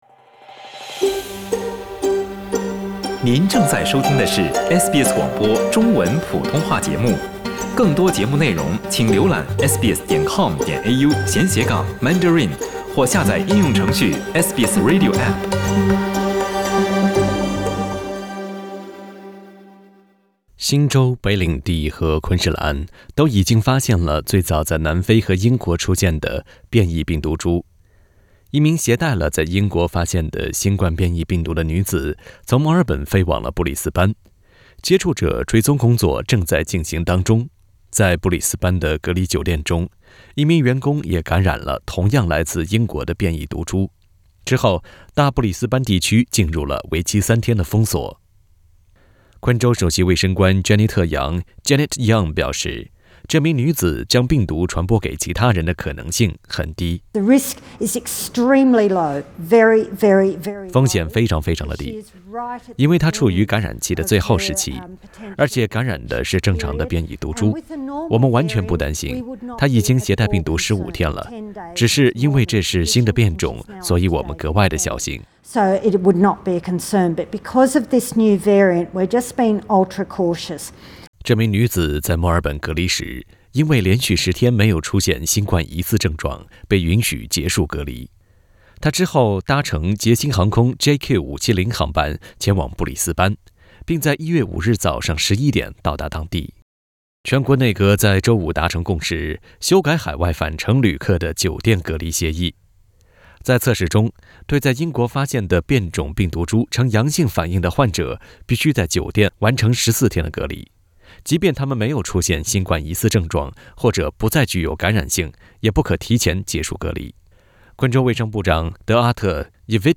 澳大利亚各州和领地正不断新冠病毒变种病例。当局表示，他们已经修改了隔离规定，正在努力避免新一轮疫情的爆发。（欢迎点击图片音频，收听报道。）